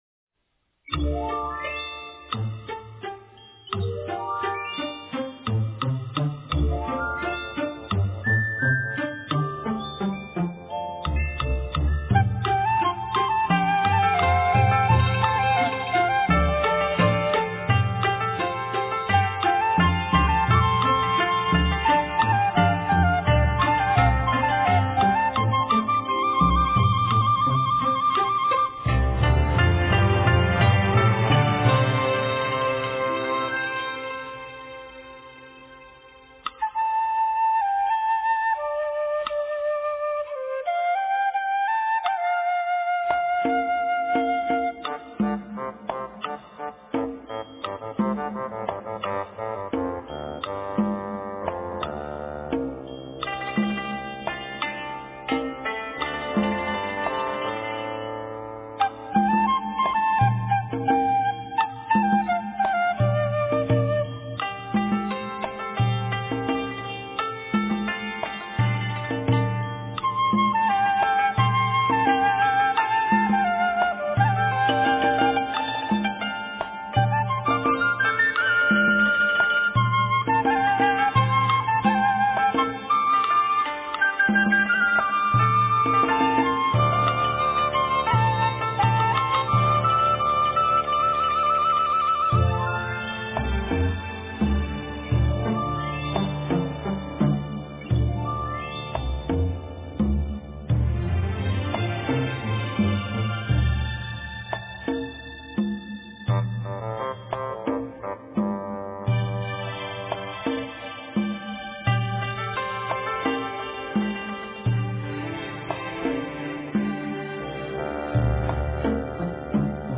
佛音 诵经 佛教音乐 返回列表 上一篇： 心经-梵音 下一篇： 大悲咒 相关文章 爱修行更爱渡众--佛教音乐 爱修行更爱渡众--佛教音乐...